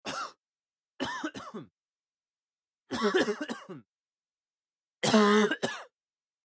Звуки першения в горле
На этой странице собраны различные звуки першения в горле – от легкого покашливания до навязчивого дискомфорта.